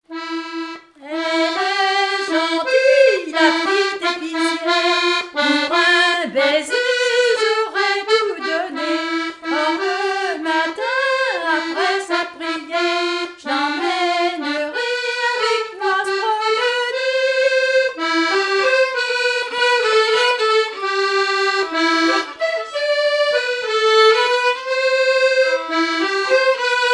Mémoires et Patrimoines vivants - RaddO est une base de données d'archives iconographiques et sonores.
danse : valse
Pièce musicale inédite